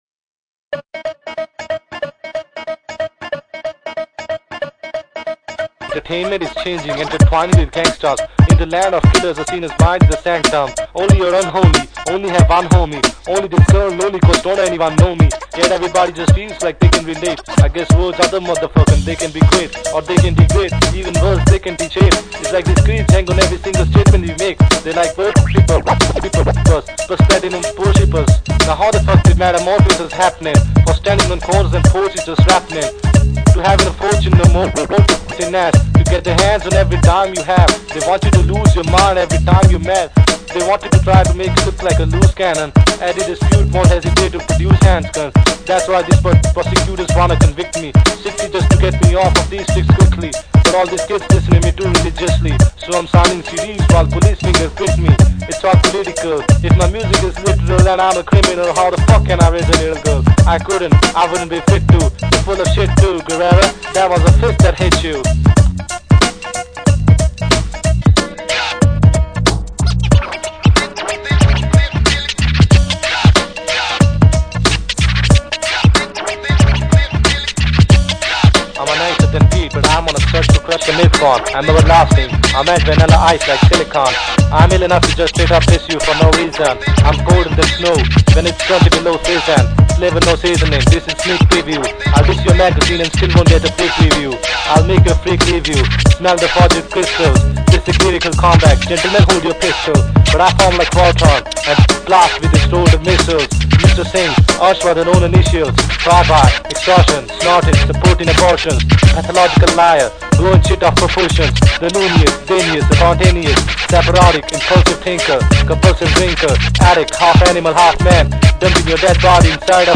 RaP Free style